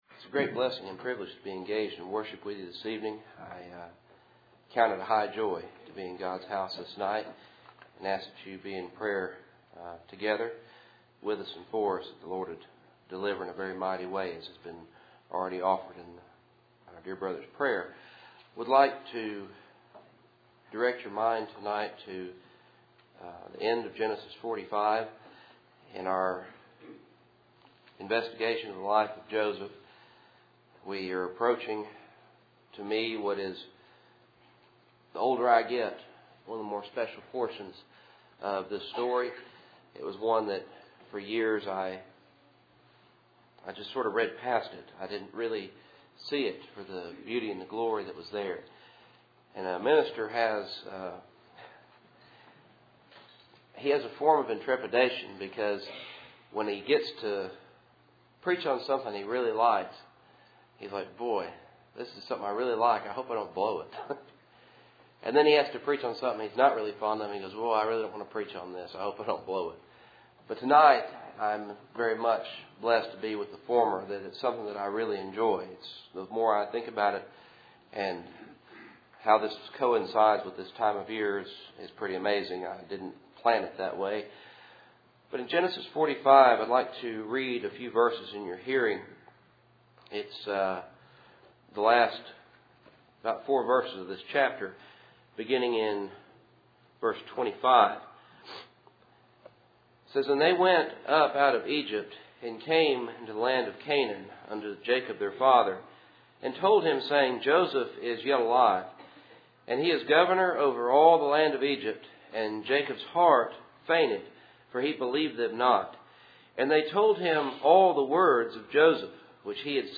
Sunday Evening